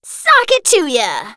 jess_kill_02.wav